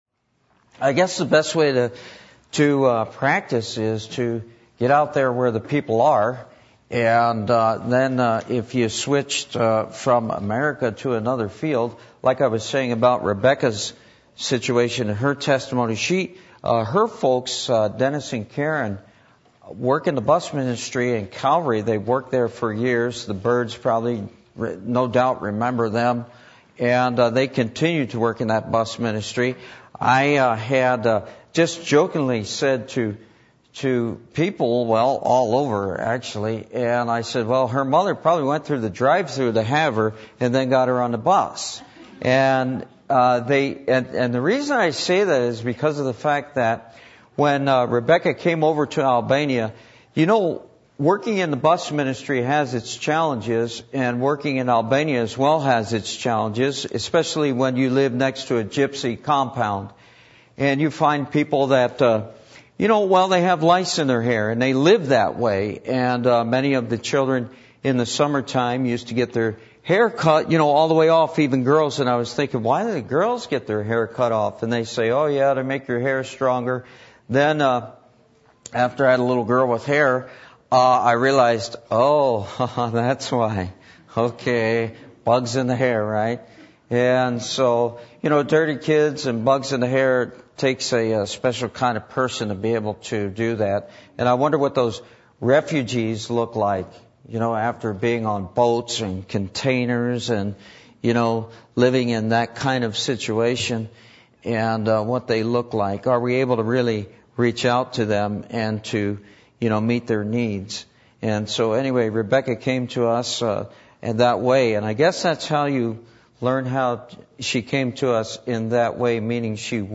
Matthew 28:16-20 Service Type: Midweek Meeting %todo_render% « What Is Baptism?